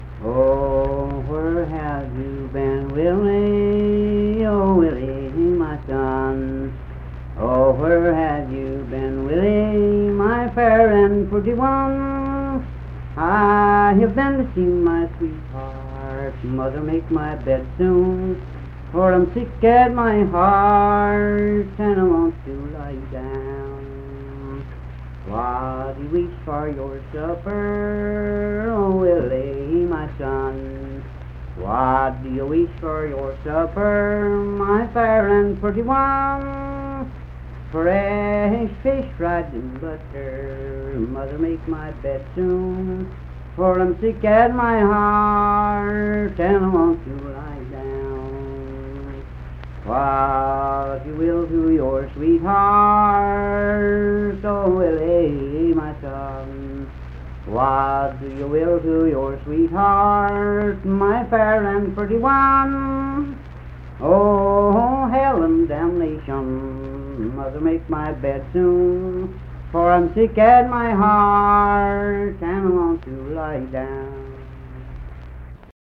Unaccompanied vocal music
Verse-refrain 3(8w/R).
Voice (sung)